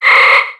Audio / SE / Cries / KIRLIA.ogg